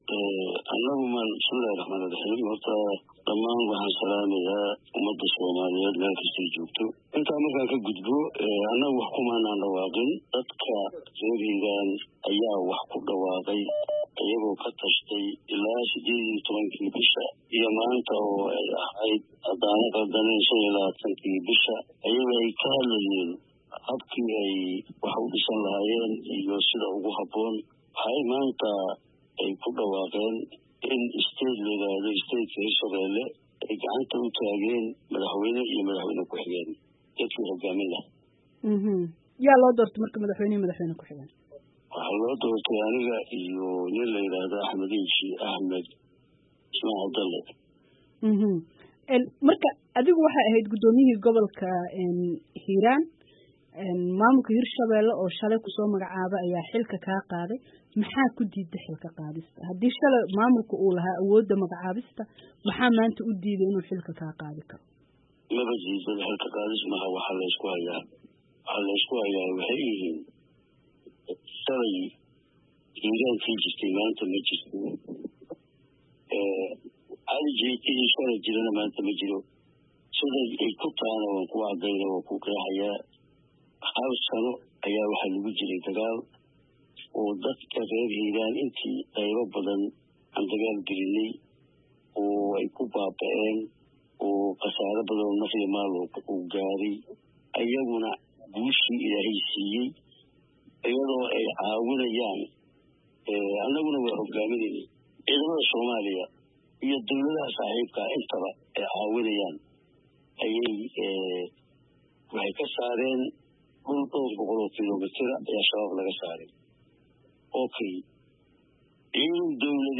Wareysi: Cali Jeyte "Shacabka ayaa madaxweyne ii doortay"